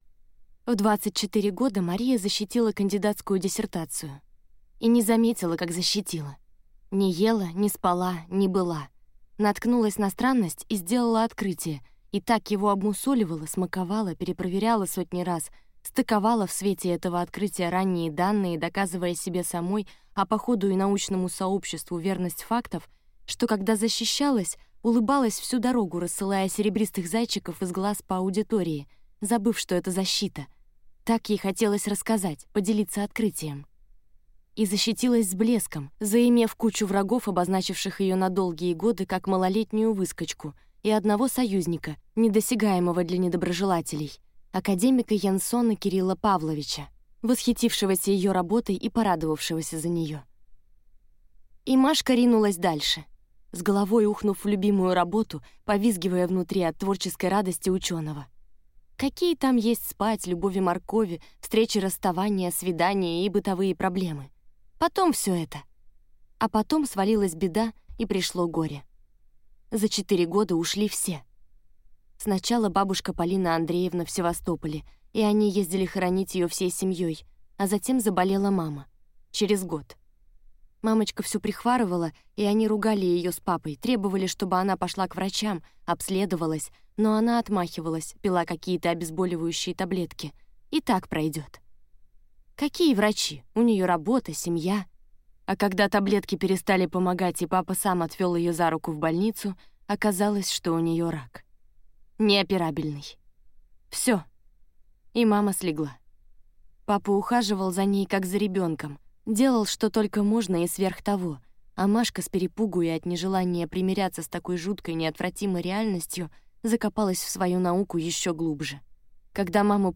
Аудиокнига Девочка моя, или Одна кровь на двоих | Библиотека аудиокниг